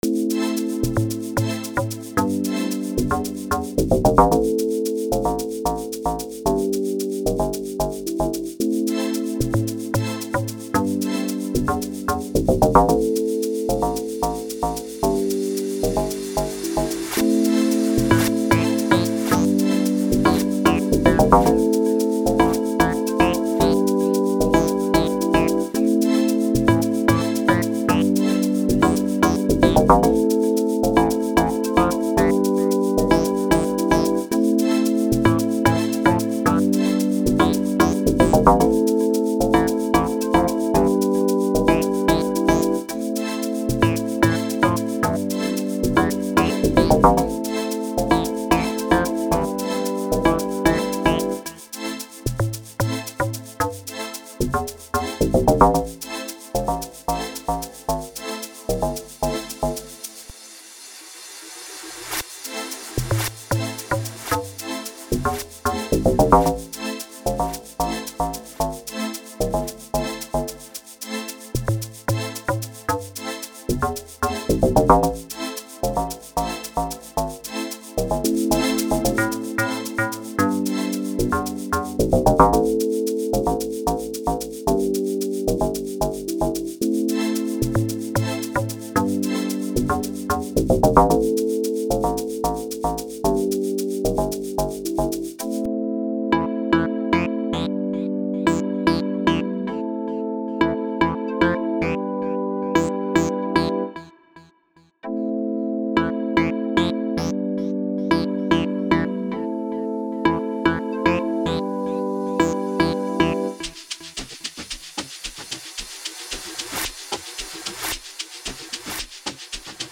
02:14 Genre : Amapiano Size